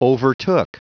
Prononciation du mot overtook en anglais (fichier audio)
Prononciation du mot : overtook